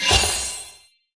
sfx_reward 01.wav